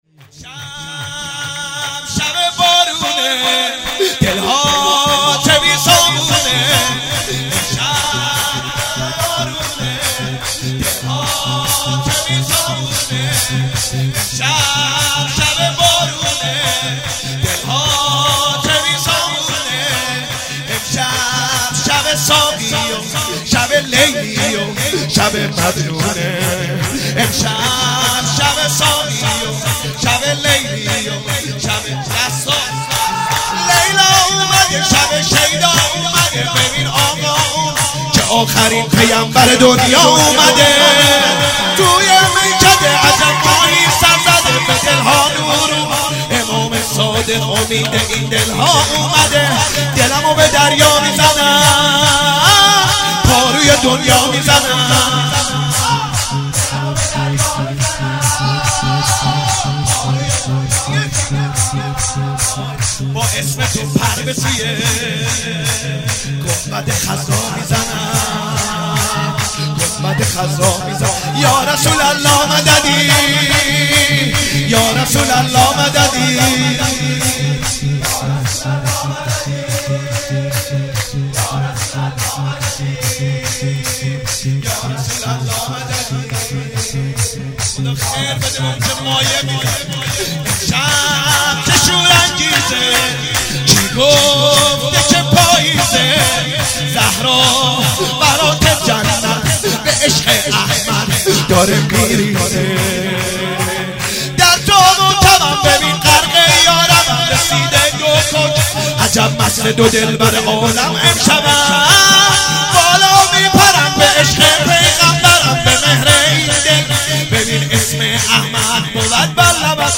شور امشب شب بارونه